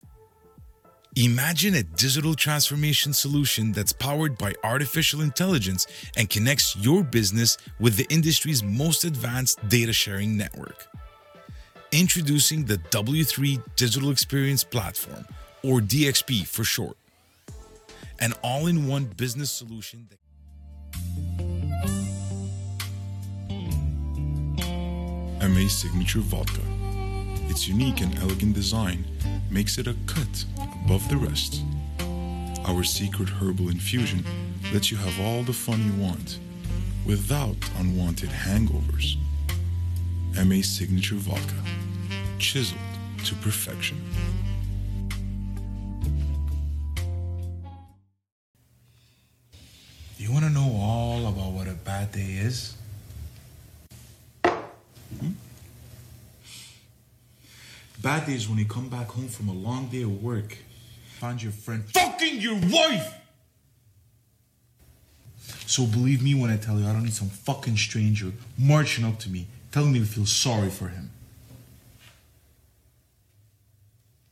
Démo audio
Demo-Reel-Audio_Voiceover.mp3